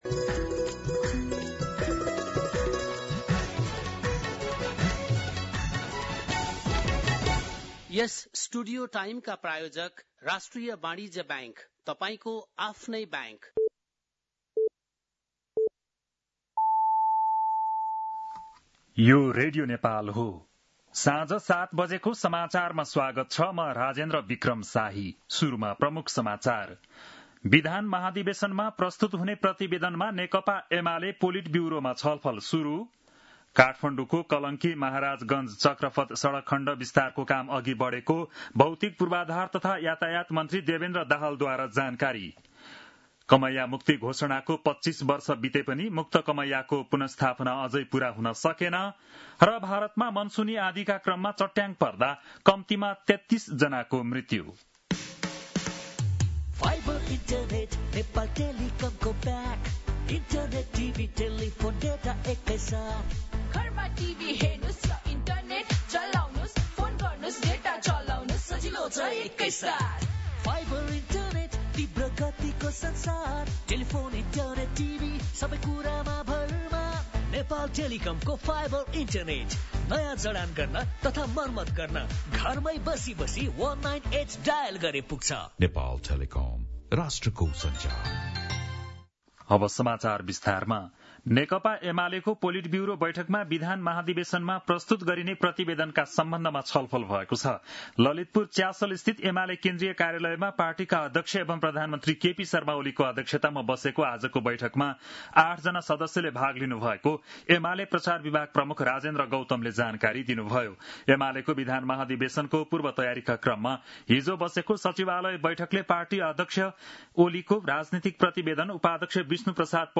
बेलुकी ७ बजेको नेपाली समाचार : २ साउन , २०८२